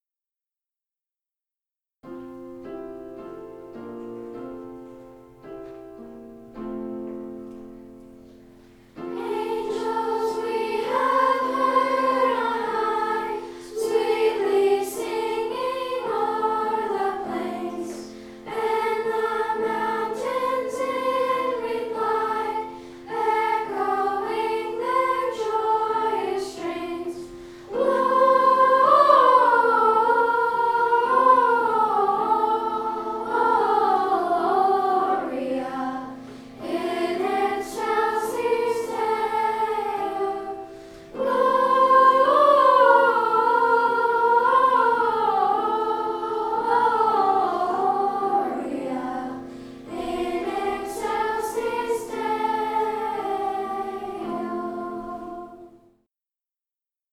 Living Nativity 2013 - Angels We Have Heard on High